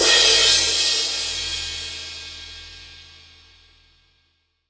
• Big Room Drum Crash Sample E Key 03.wav
Royality free crash cymbal drum sound tuned to the E note. Loudest frequency: 4825Hz
big-room-drum-crash-sample-e-key-03-64p.wav